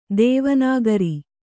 ♪ dēva nāgari